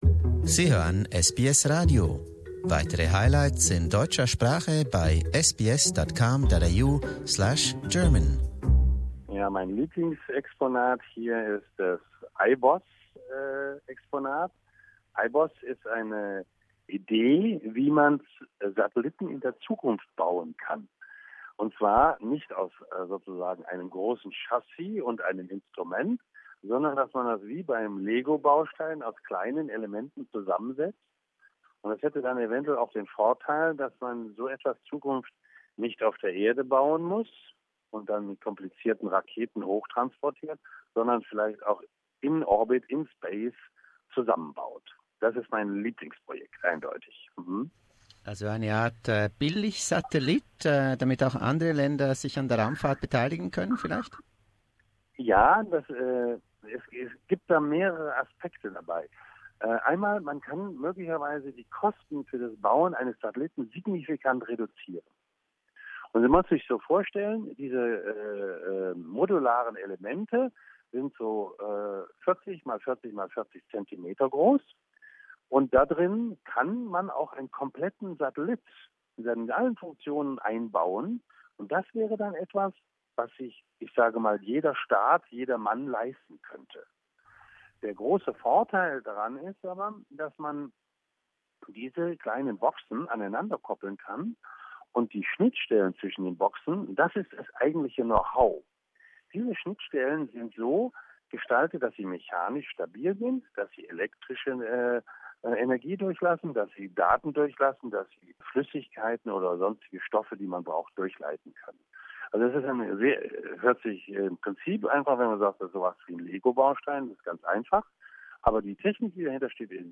SBS Interview